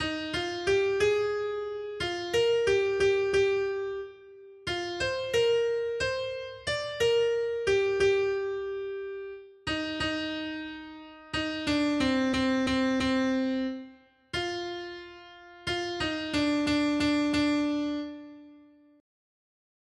Noty Štítky, zpěvníky ol717.pdf responsoriální žalm Žaltář (Olejník) 717 Skrýt akordy R: Budu tě chválit, Hospodine, ve velkém shromáždění. 1.